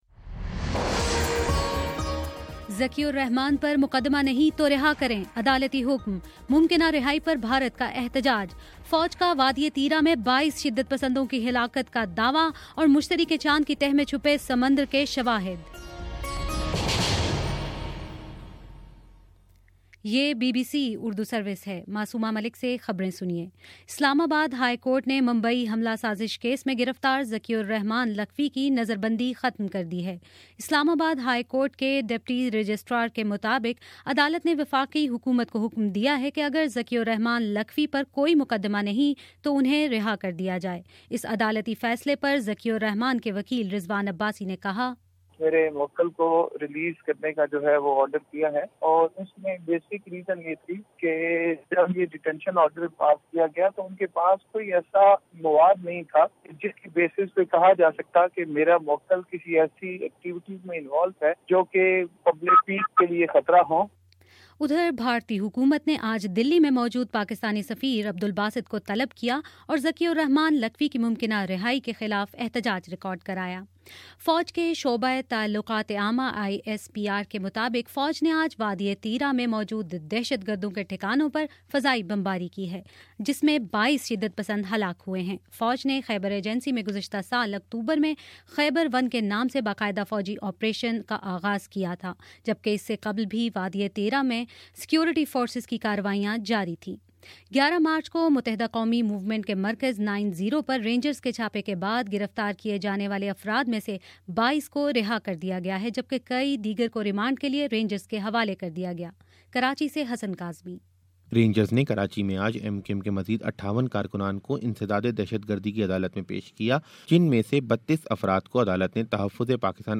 مارچ 13: شام سات بجے کا نیوز بُلیٹن